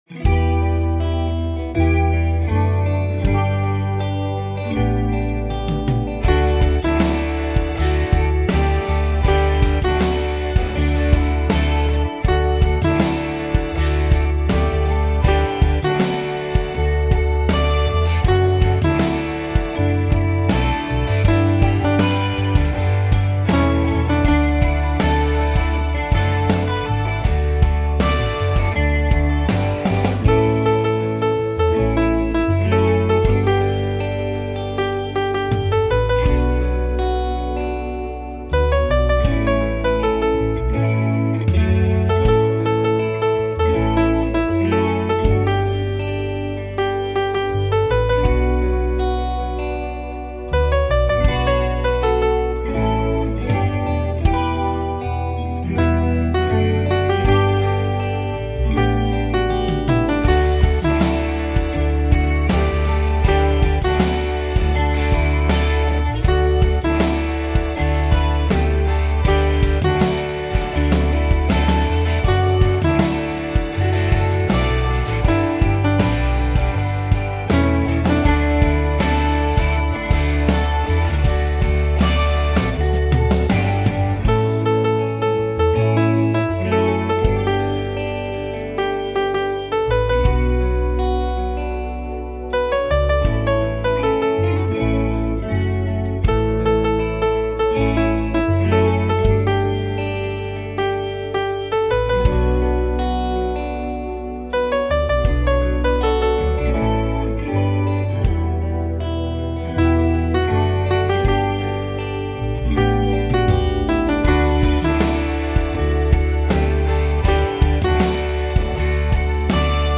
I’ve sped up up my backing just a little.